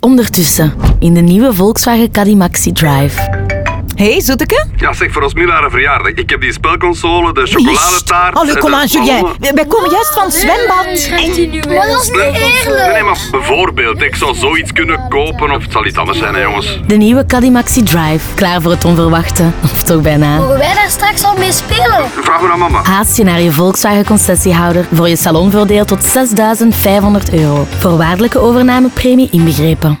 Radiospot 2